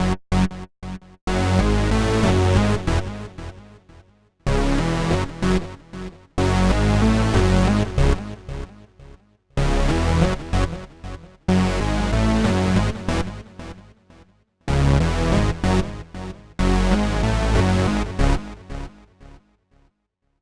Ridin_ Dubs - Rezo Bass.wav